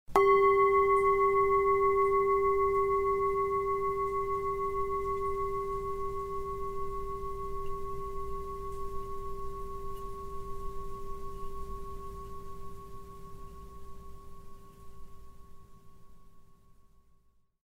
tibetskii-gong_24618.mp3